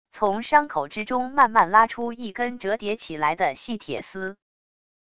从伤口之中慢慢拉出一根折叠起来的细铁丝wav音频